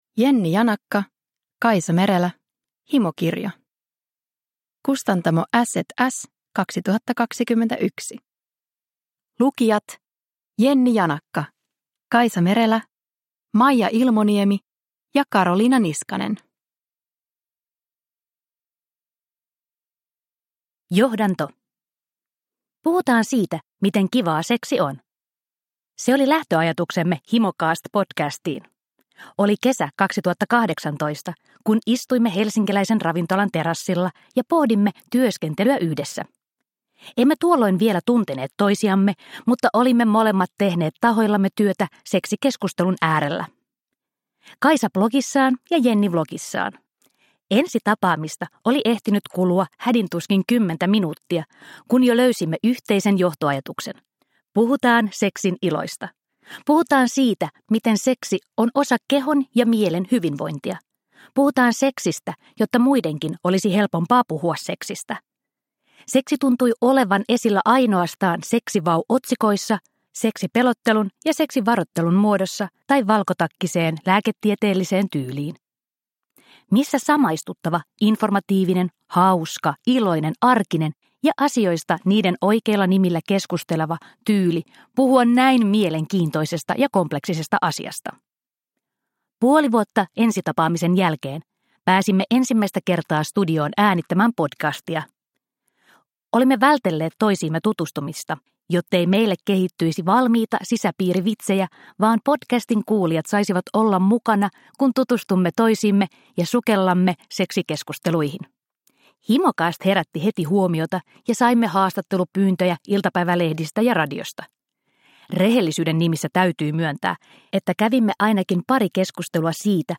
Himokirja – Ljudbok – Laddas ner